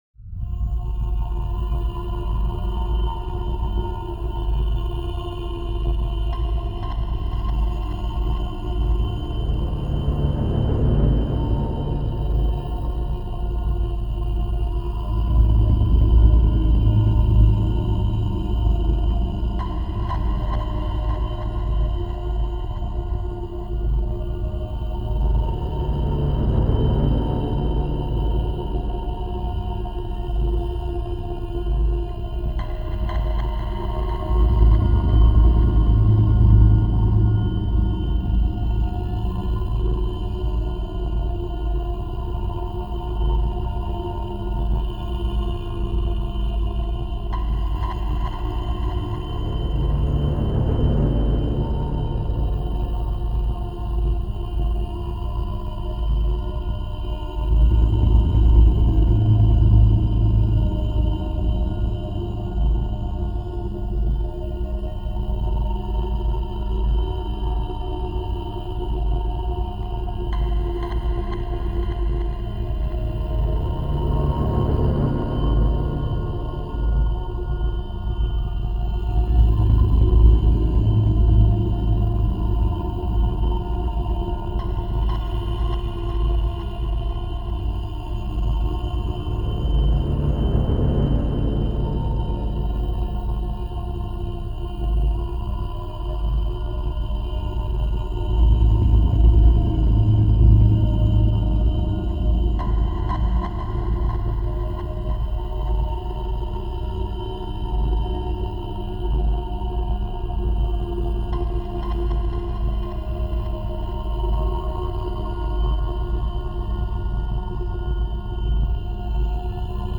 free horror ambience 2